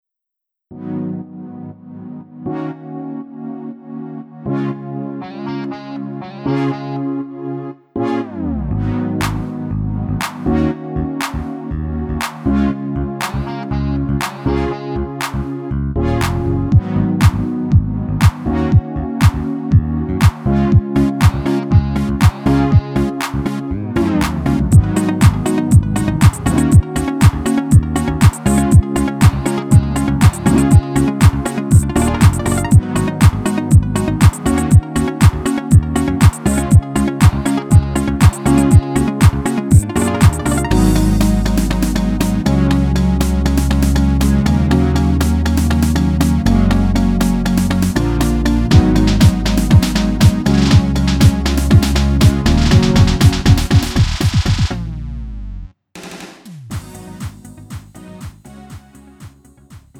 축가 및 결혼식에 최적화된 고품질 MR을 제공합니다!
음정 -1키
장르 가요